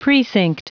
Prononciation du mot precinct en anglais (fichier audio)
Prononciation du mot : precinct